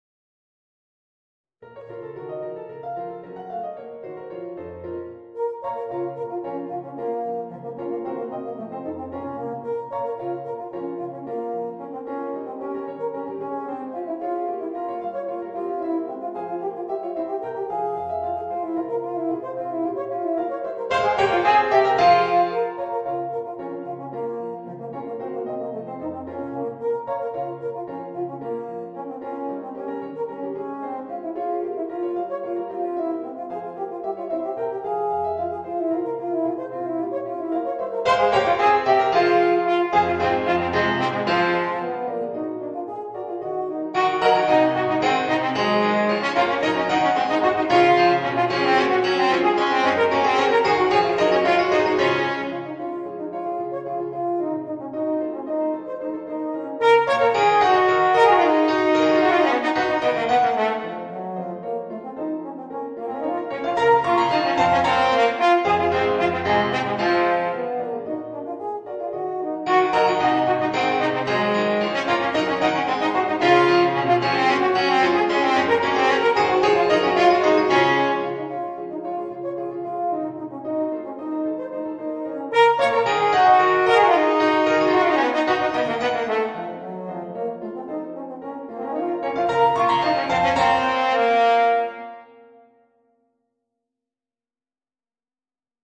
Es-Horn & Klavier